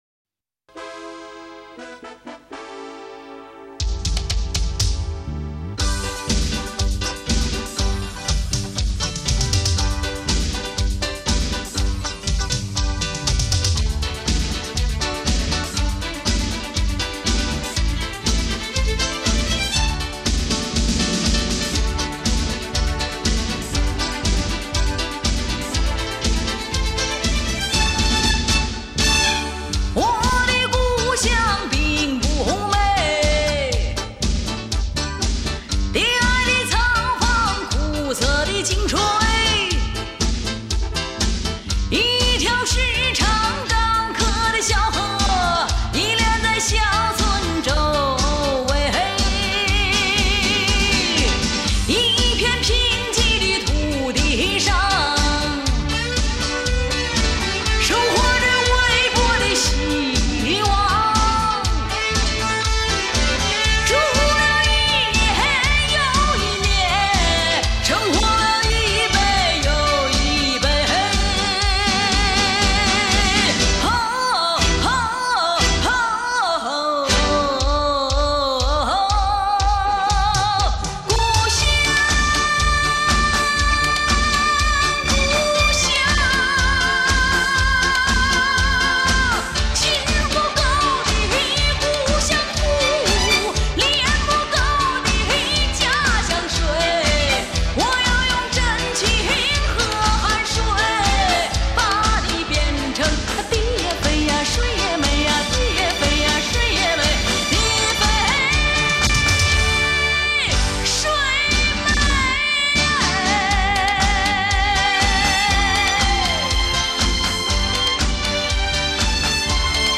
一曲天籁之音，仿佛从仙山神寺飘来……